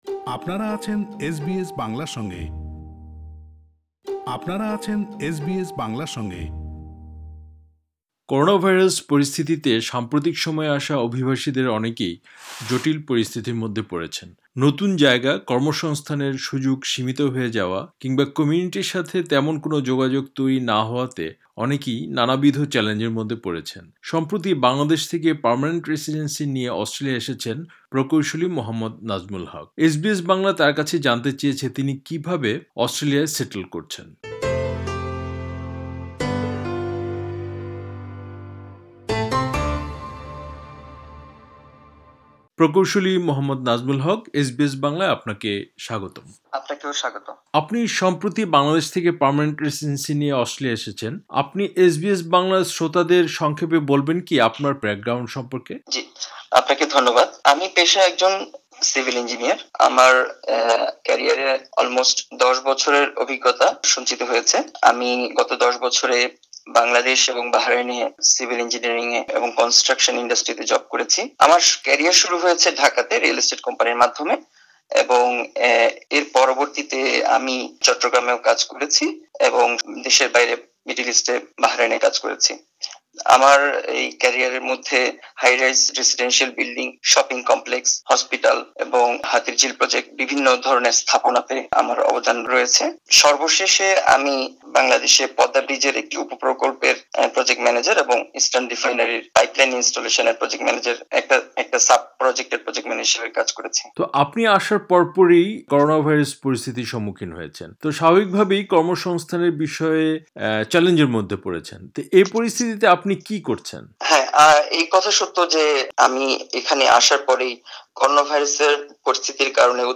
Supplied পুরো সাক্ষাতকারটি শুনতে ওপরের অডিও প্লেয়ারটি ক্লিক করুন আরও পড়ুনঃ READ MORE সেটেলমেন্ট গাইড